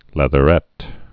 (lĕthə-rĕt)